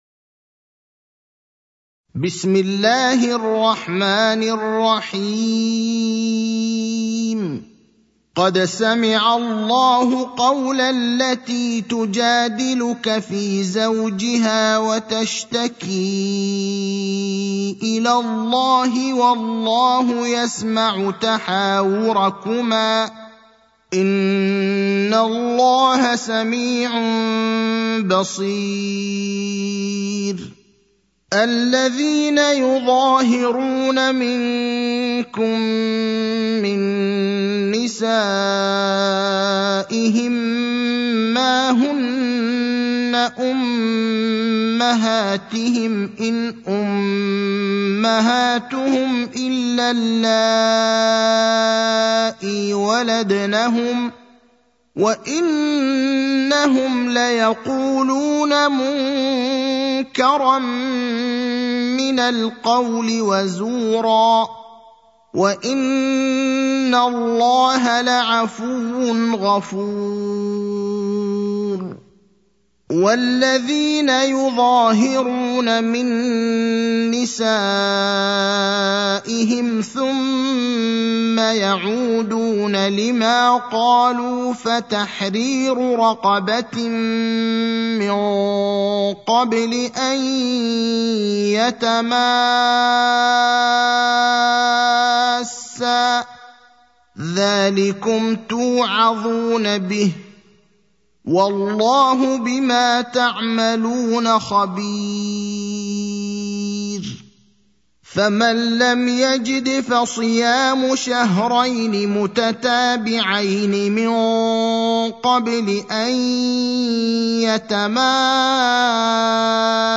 المكان: المسجد النبوي الشيخ: فضيلة الشيخ إبراهيم الأخضر فضيلة الشيخ إبراهيم الأخضر المجادلة (58)